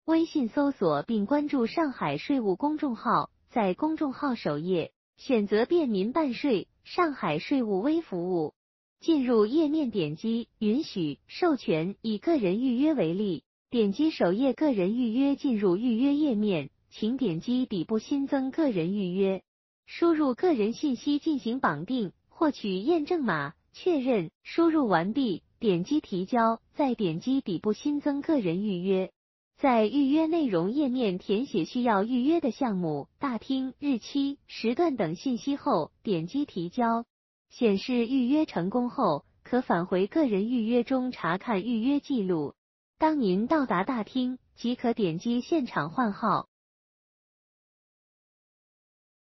上海市税务局在全市范围内推行“全预约”办税，来看税务小姐姐教您如何预约办税，办税缴费“快人一步”。